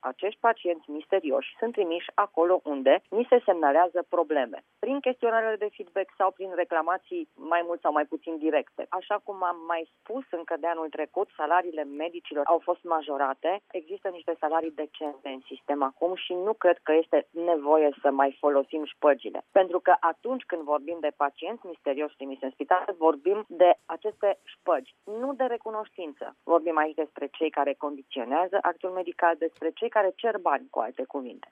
Așa a declarat, în această dimineaţă, la RRA, ministrul de resort, Sorina Pintea.